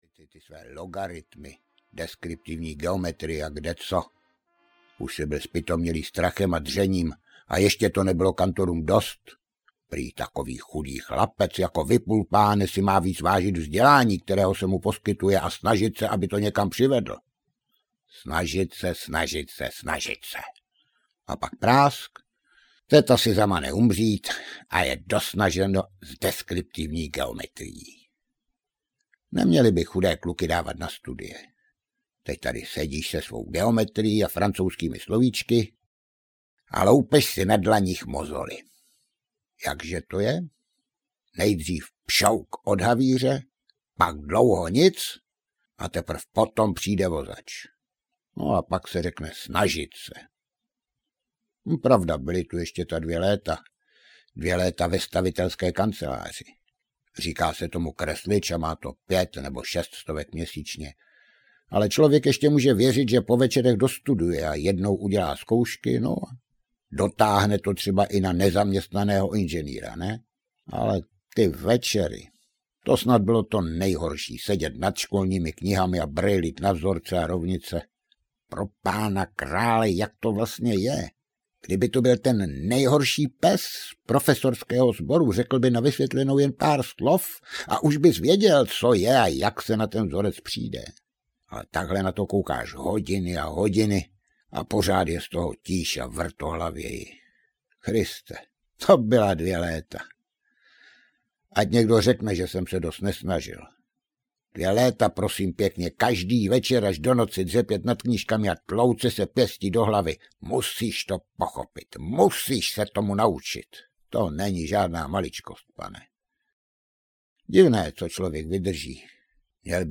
První parta audiokniha
Ukázka z knihy